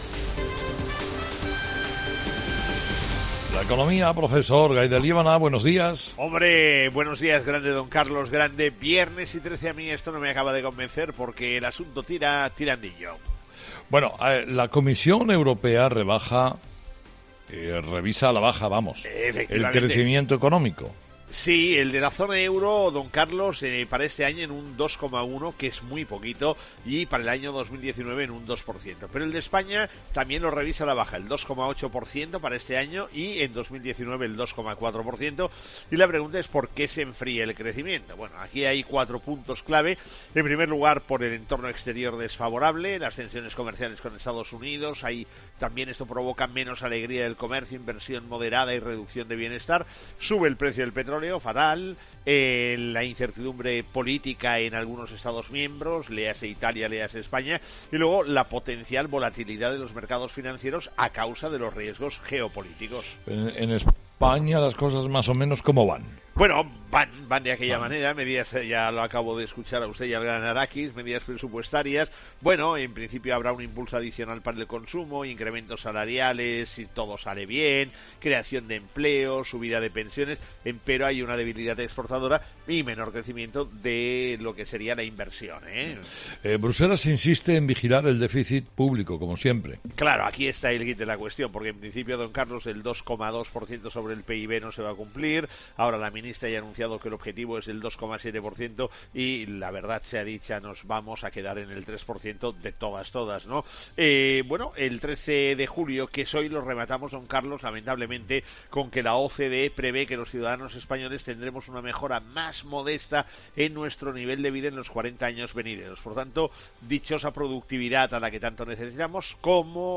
Escucha ahora: El primer análisis económico de la mañana con el profesor Gay de Liébana en ‘Herrera en COPE’.